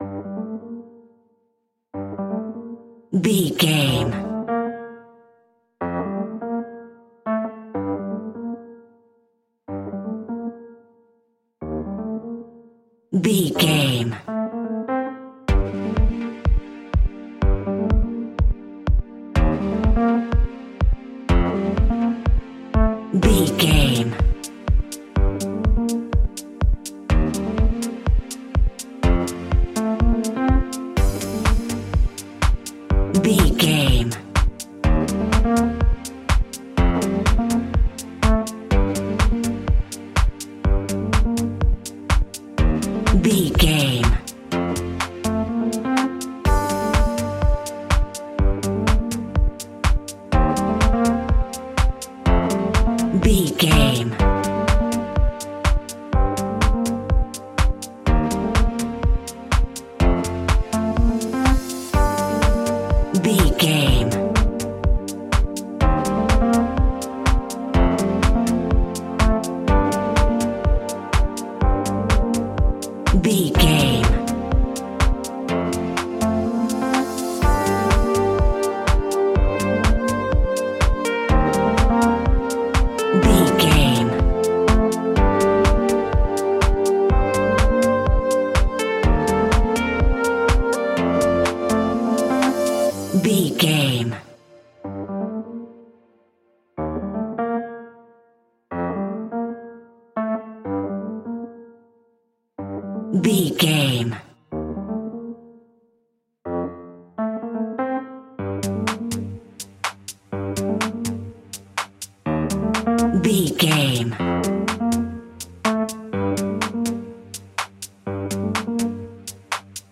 Aeolian/Minor
groovy
synthesiser
drum machine
electric piano
funky house
deep house
nu disco
upbeat
funky guitar
synth bass